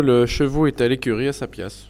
Île-d'Olonne (L')
Langue Maraîchin
Catégorie Locution